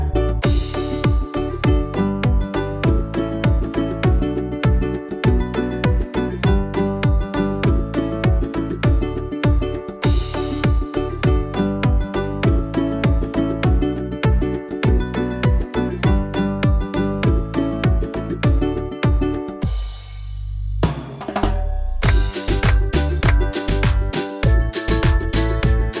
utwór w wersji instrumentalnej